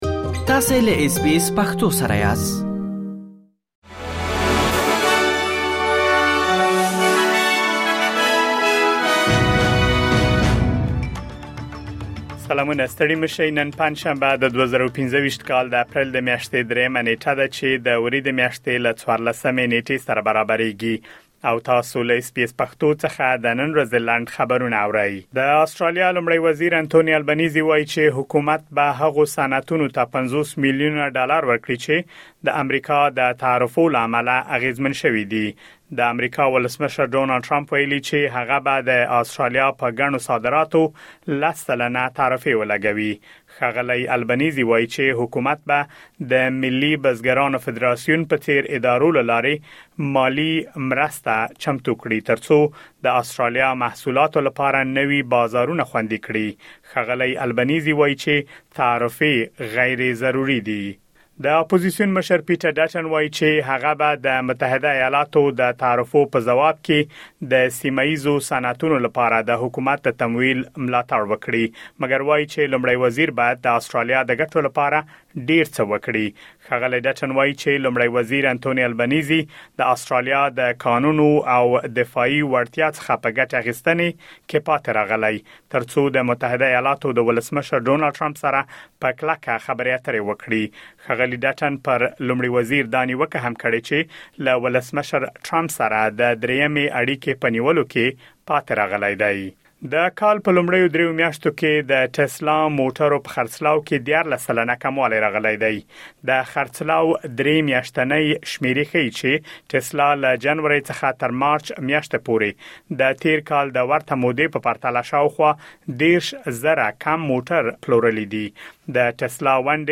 د اس بي اس پښتو د نن ورځې لنډ خبرونه | ۳ اپریل ۲۰۲۵